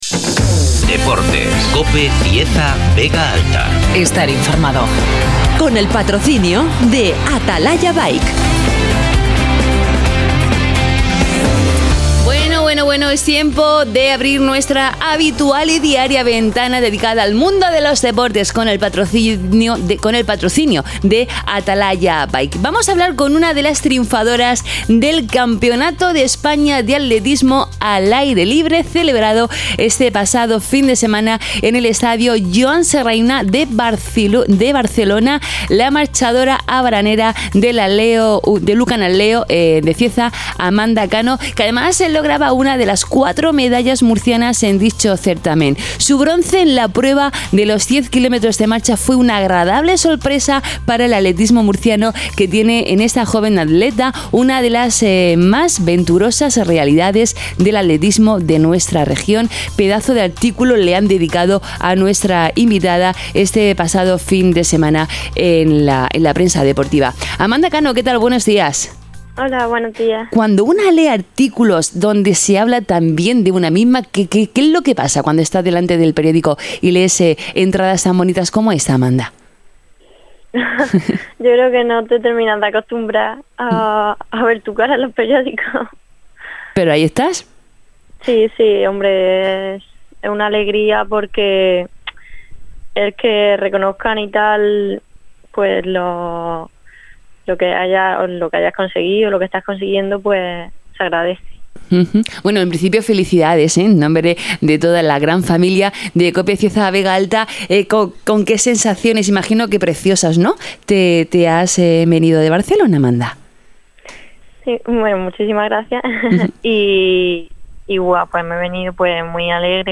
Entrevista Cope Cieza